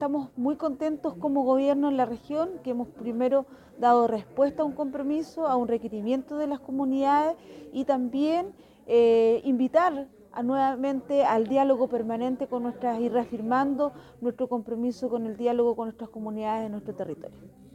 CUNA-DELEGADA-CARLA_PENA.mp3